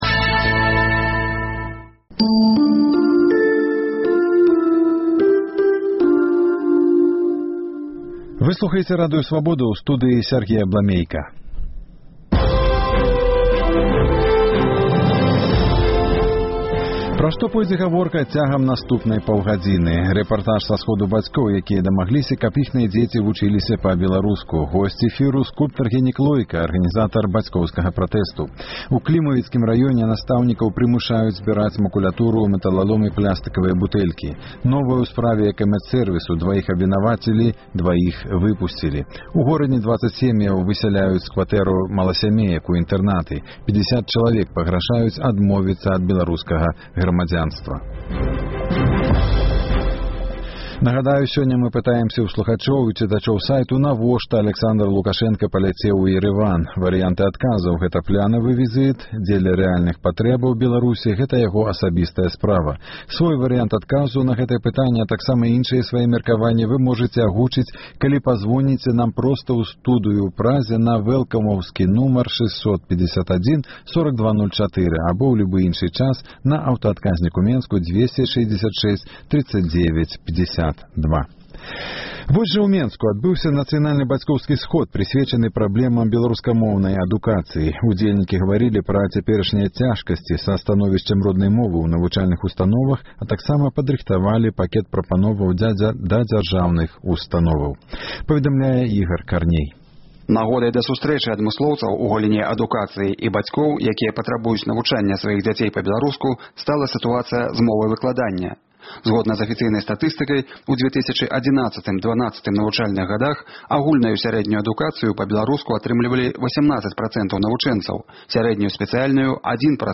Новае ў справе «Экамэдсэрвісу»: дваіх абвінавацілі, дваіх выпусьцілі У Горадні 20 сем’яў высяляюць з кватэраў-маласямеек у інтэрнаты. 50 чалавек пагражаюць адмовіцца ад беларускага грамадзянства. Навіны з жыцьця палітвязьняў Беларусі. Апытаньне: чаму, нягледзячы на вялікія дзяржаўныя субсыдыі, беларускія хакеісты так няўдала выступаюць?